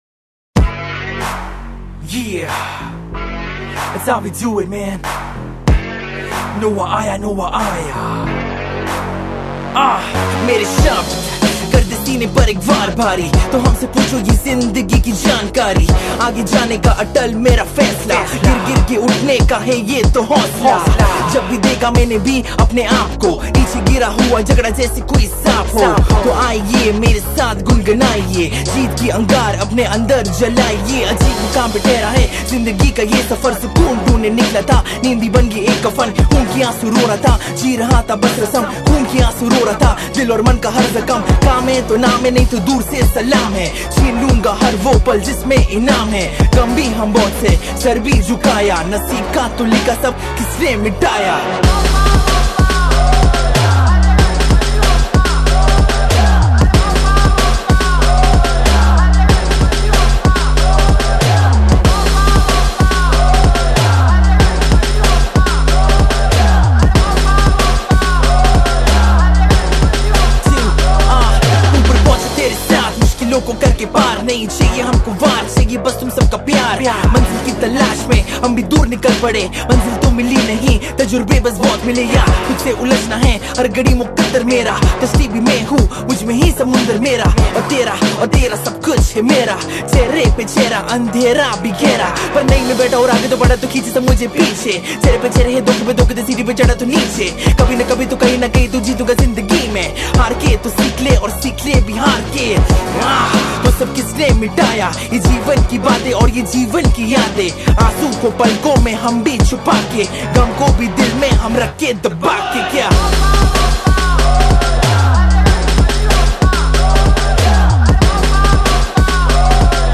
Pop Songs